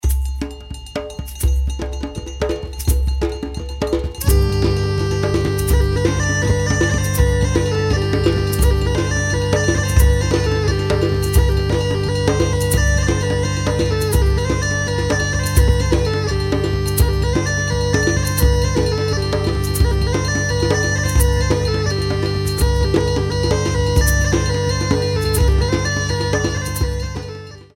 six beats